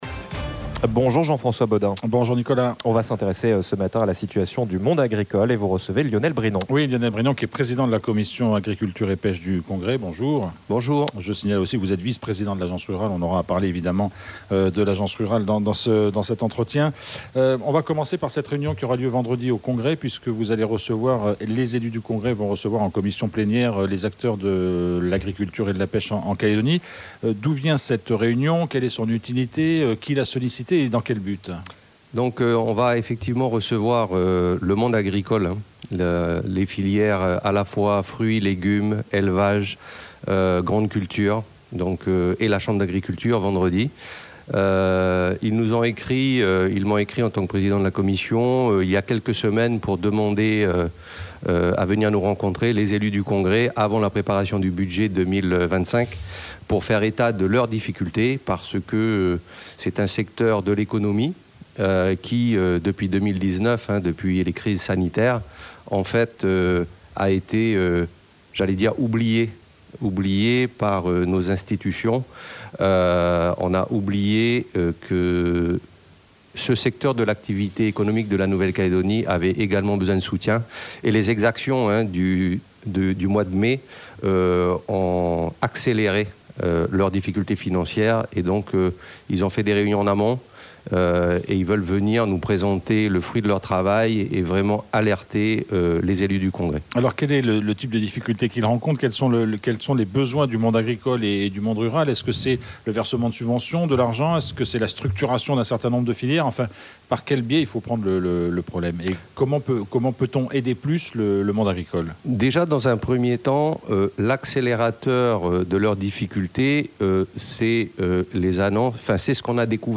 Le président de la commission agriculture et pêche du congrès était en effet notre invité à 7h30.